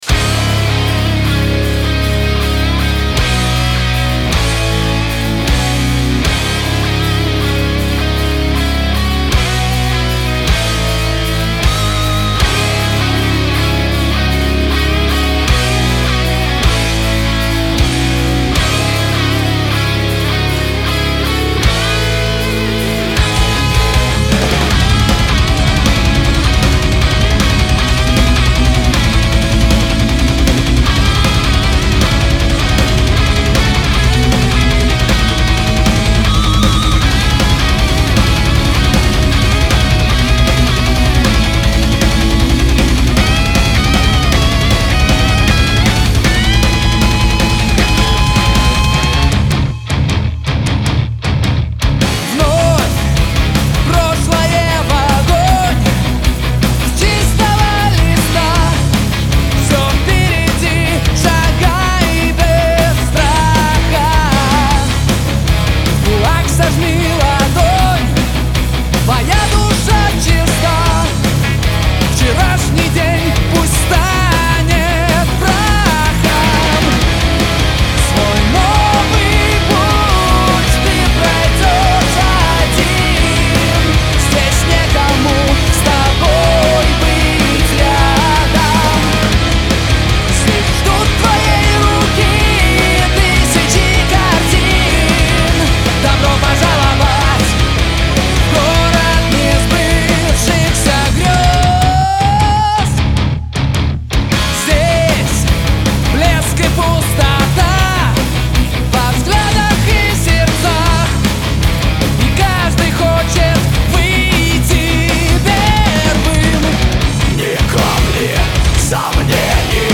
вокал
гитара
бас
ударные
Звук качественный!